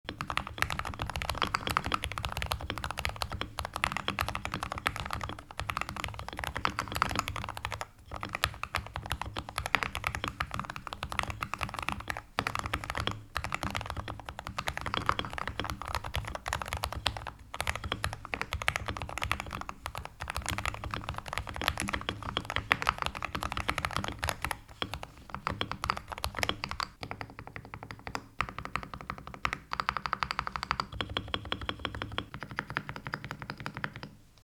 typing_test.mp3